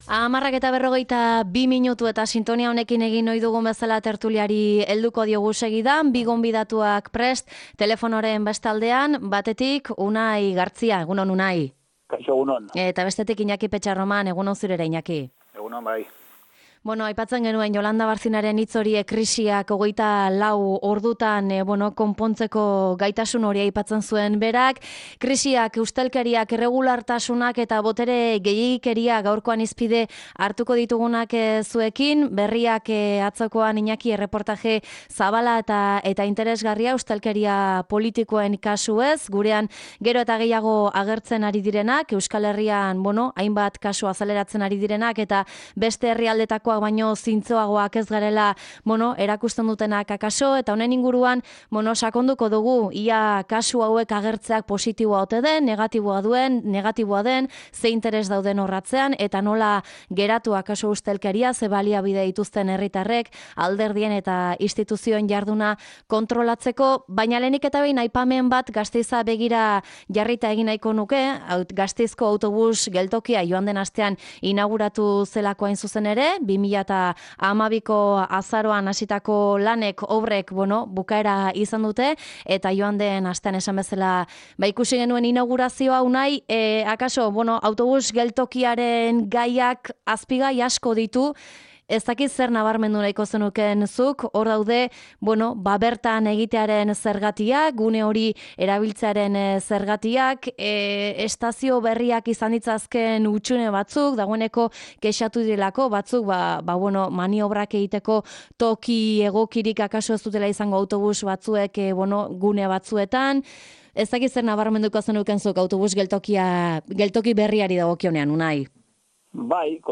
Tertulia: ustelkeria, kasu bakanak ala sistema oso bat?